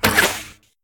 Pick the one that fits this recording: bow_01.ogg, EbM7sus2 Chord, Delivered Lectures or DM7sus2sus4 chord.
bow_01.ogg